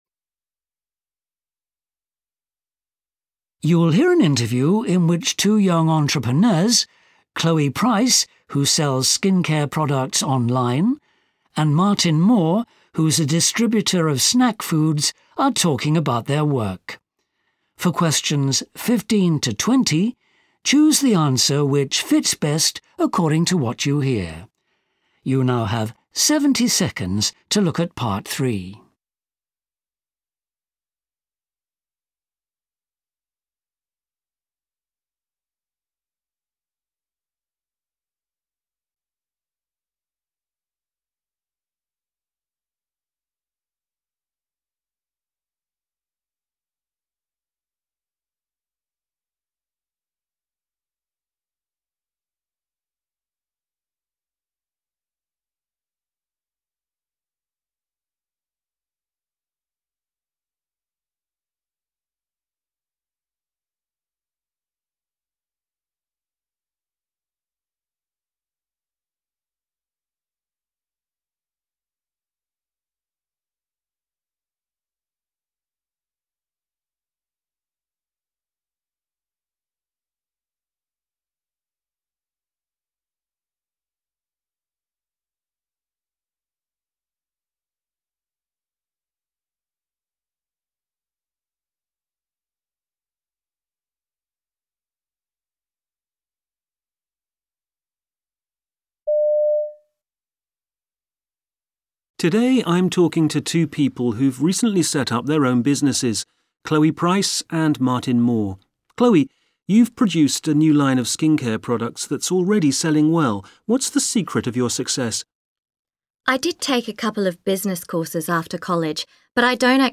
Listening_Exercise_2_Interview.mp3